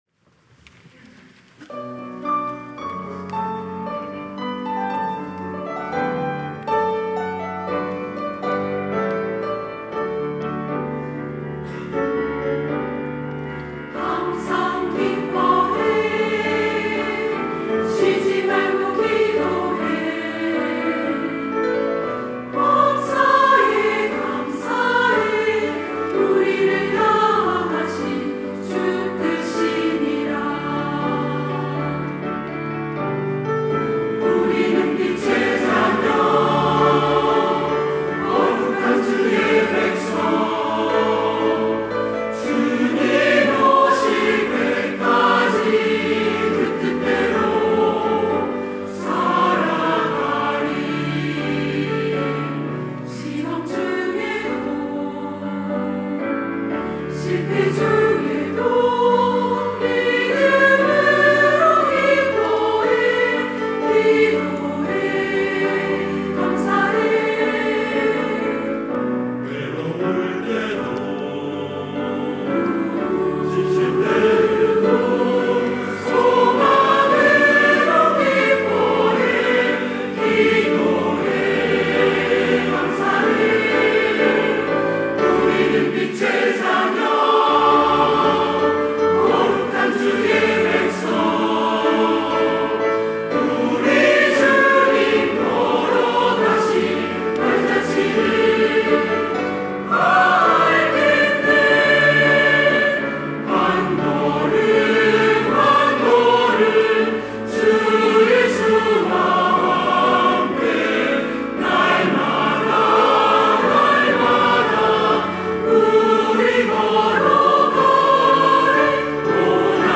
시온(주일1부) - 기뻐해 기도해 감사해
찬양대